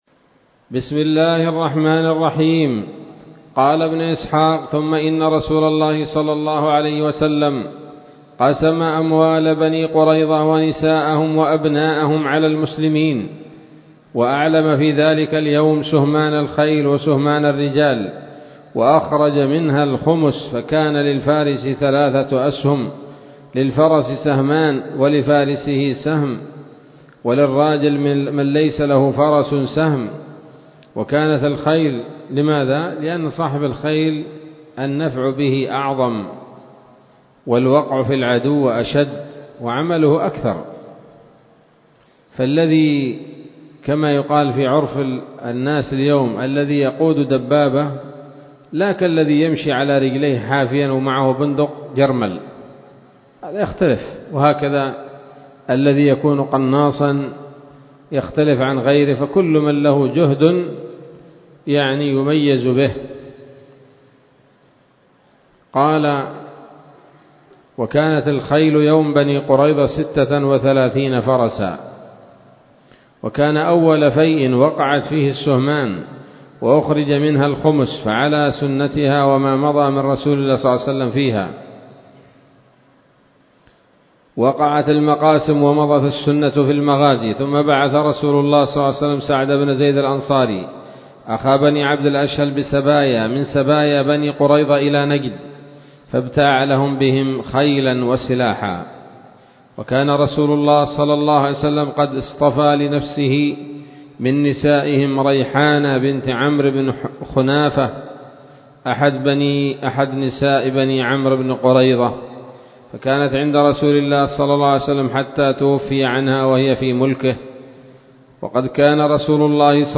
الدرس العاشر بعد المائتين من التعليق على كتاب السيرة النبوية لابن هشام